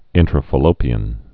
(ĭntrə-fə-lōpē-ən)